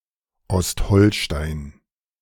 Ostholstein (German pronunciation: [ˈɔsthɔlʃtaɪn]
De-Ostholstein.ogg.mp3